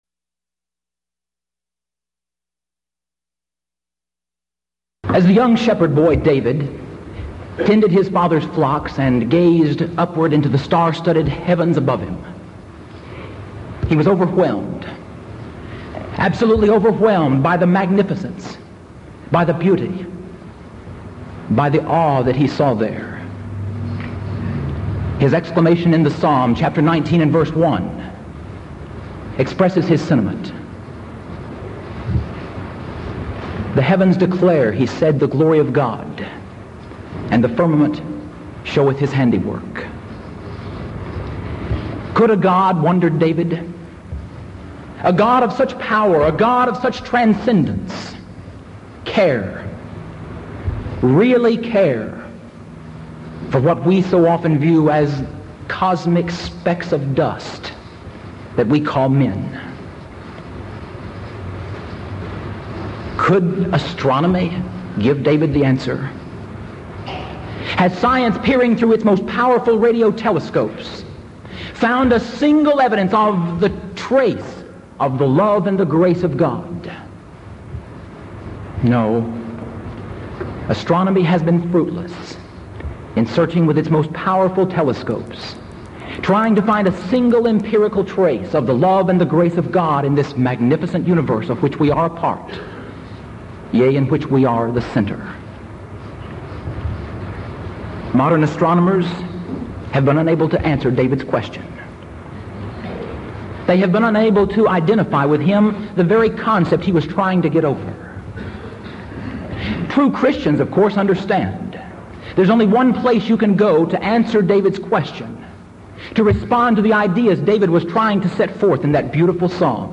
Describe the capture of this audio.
Event: 1982 Denton Lectures Theme/Title: Studies in 1 Corinthians